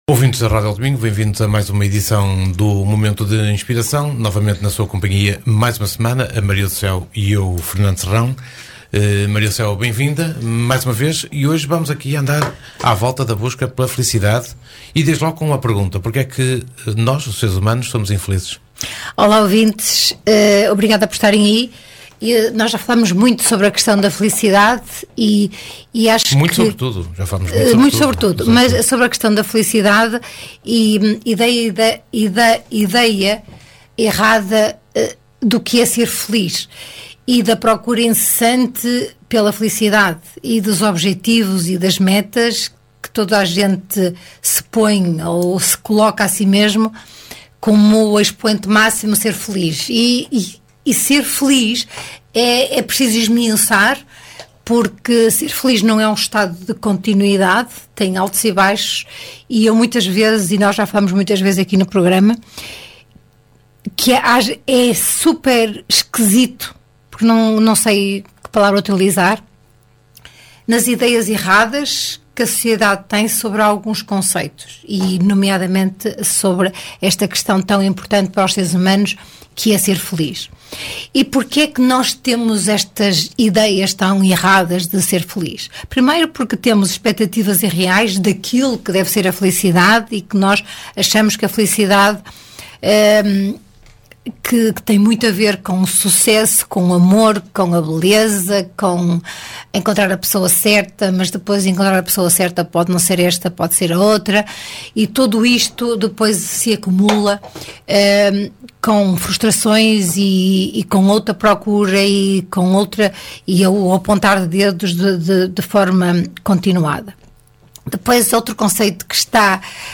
Momento de Inspiração Uma conversa a dois